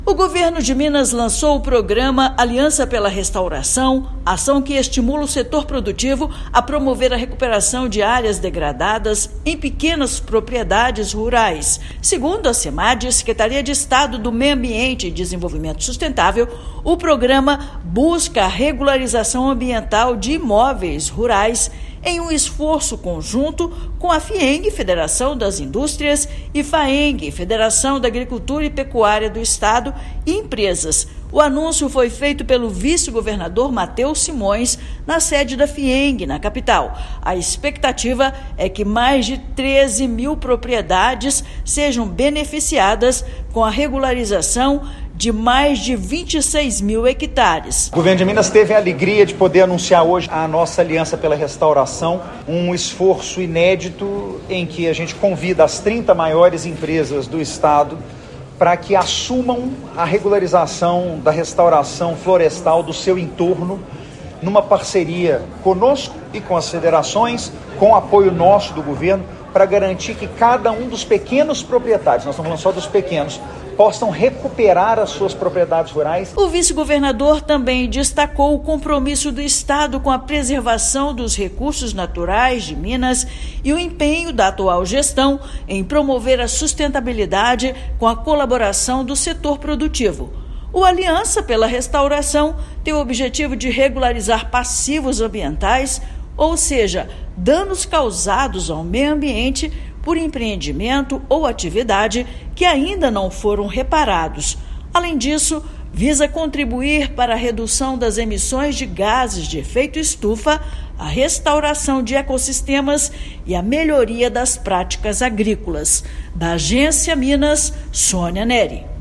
Programa Aliança pela Restauração também foca na restauração de ecossistemas e redução de emissões de gases de efeito estufa. Ouça matéria de rádio.